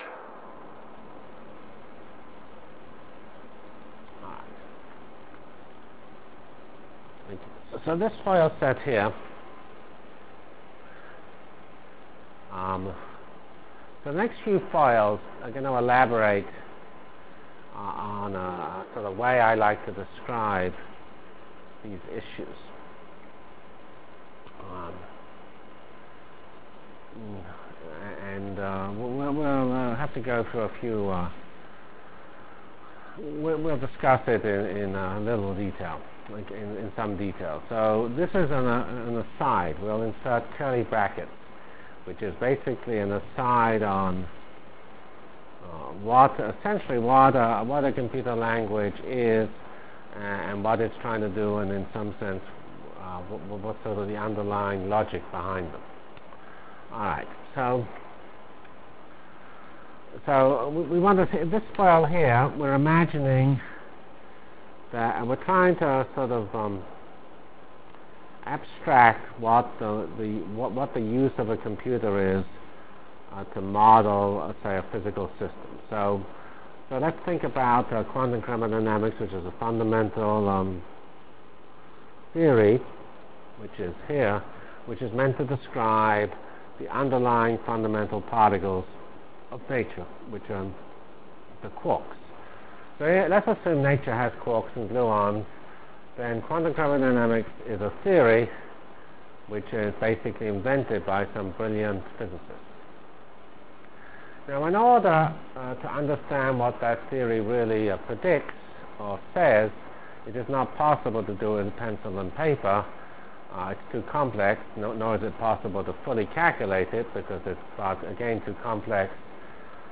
From CPS615-Introduction to F90 Features, Rationale for HPF and Problem Architecture Delivered Lectures of CPS615 Basic Simulation Track for Computational Science -- 24 September 96. by Geoffrey C. Fox